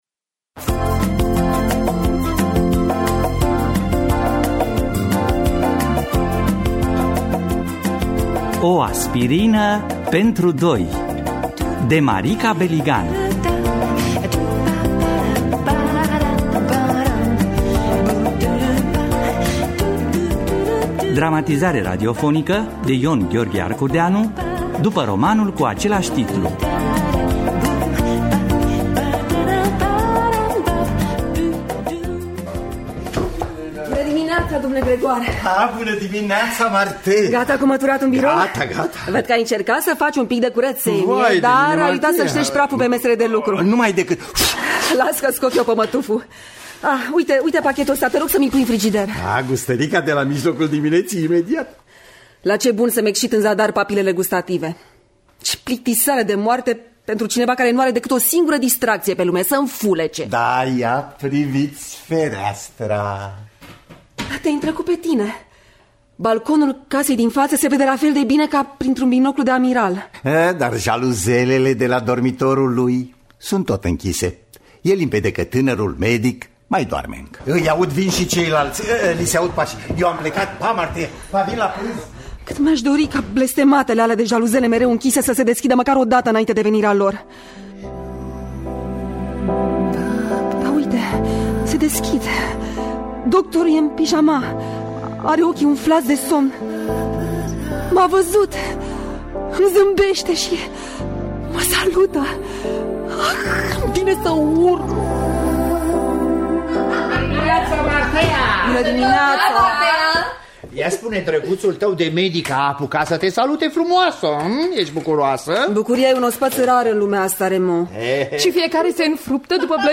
Dramatizare radiofnică de I. Gh. Arcudeanu.